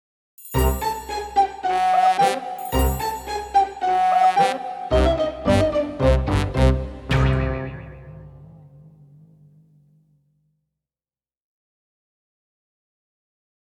Thể loại: Nhạc nền video